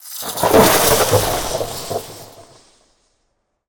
elec_lightning_magic_spell_10.wav